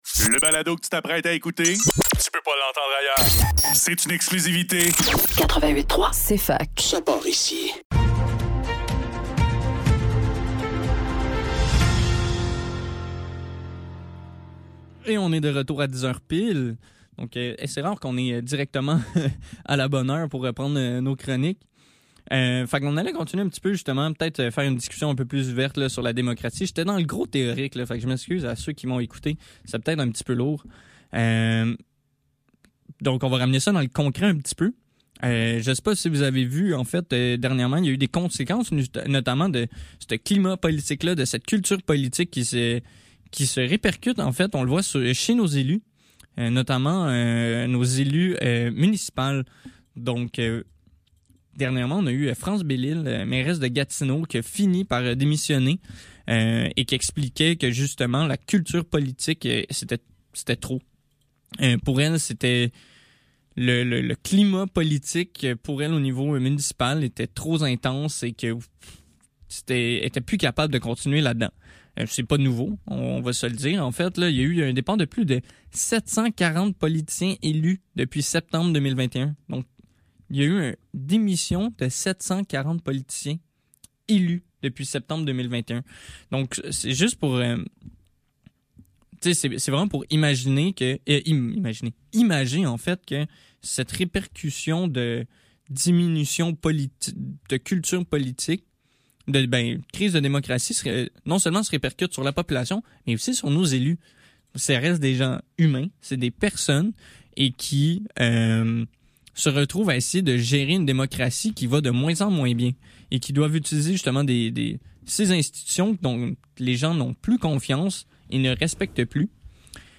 Le NEUF - Discussion démocratie - 27 février 2024